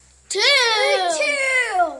描述：孩子们大喊大叫 零
Tag: 孩子 计数 数字 孩子